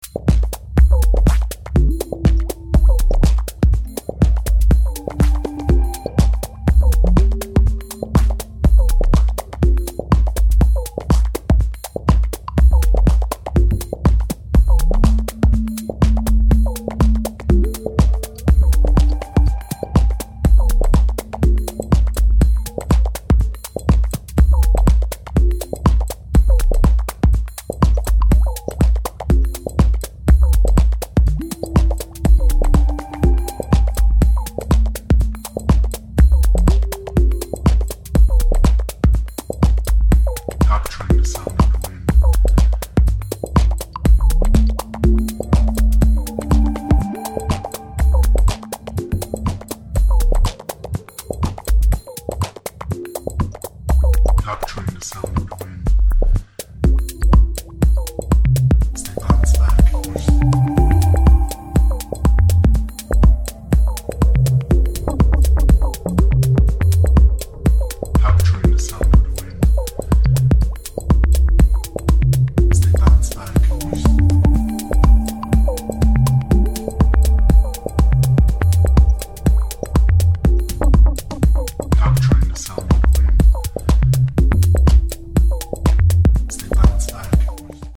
techno and house productions